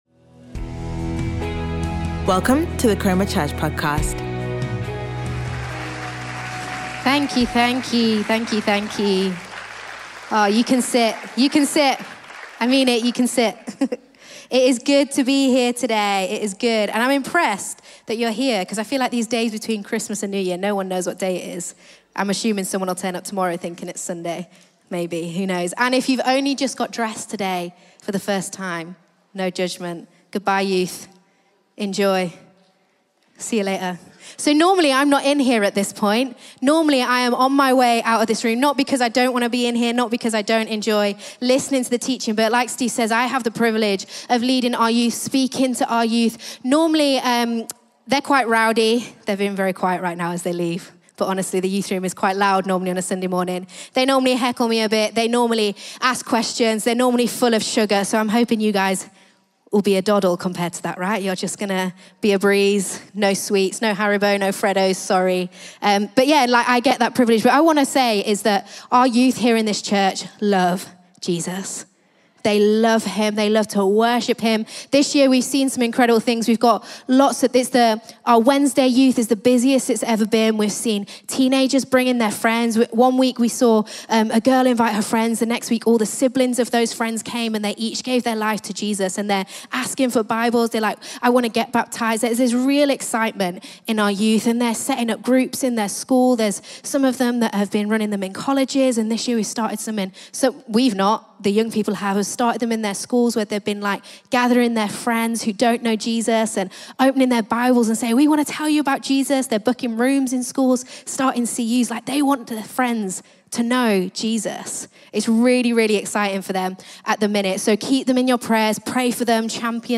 Sunday Sermon Psalm 27